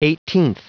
Vous êtes ici : Cours d'anglais > Outils | Audio/Vidéo > Lire un mot à haute voix > Lire le mot eighteenth
Prononciation du mot : eighteenth